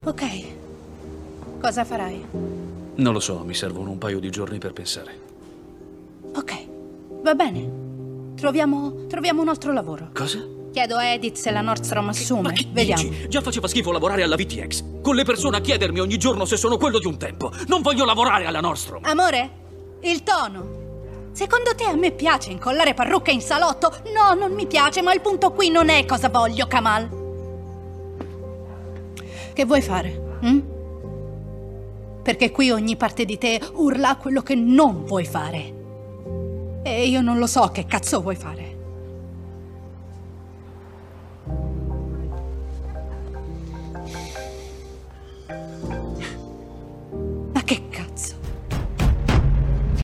nel film "White Men Can't Jump", in cui doppi Teyana Taylor.